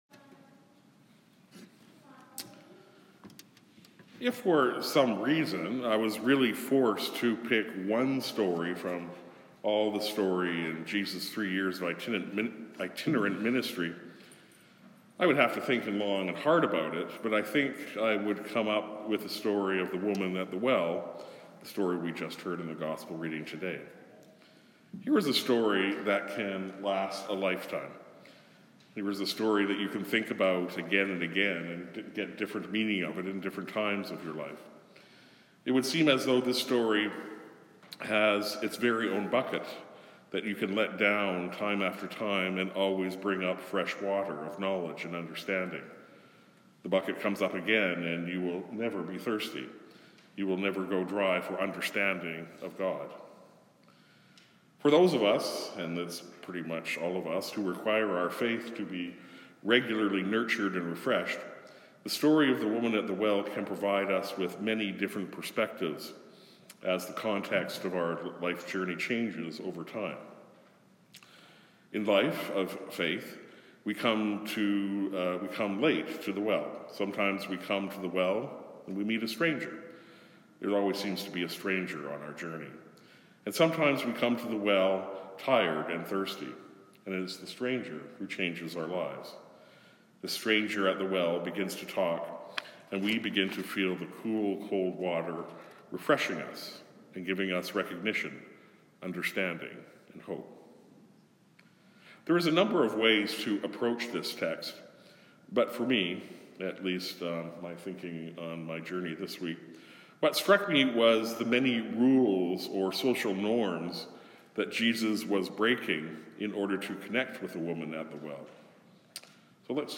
Sermons | St. John the Evangelist